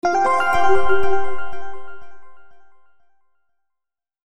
alert